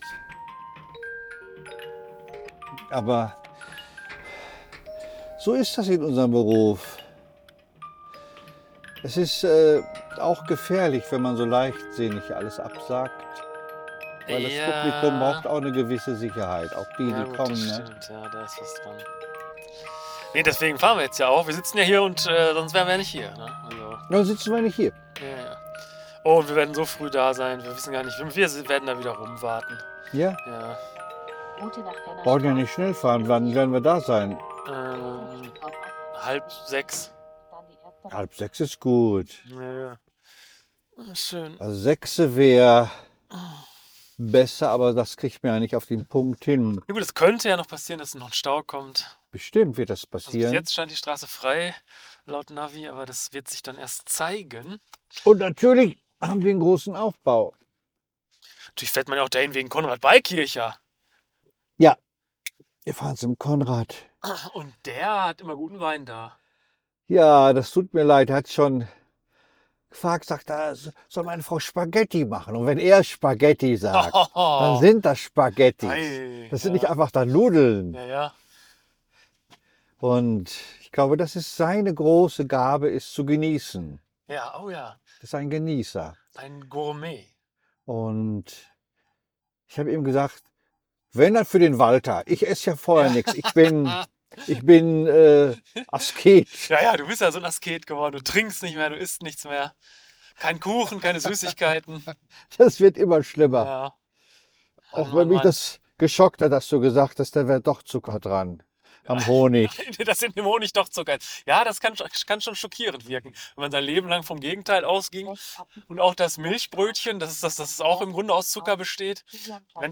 Aufgenommen live während der Fahrt zu einem Auftrittsort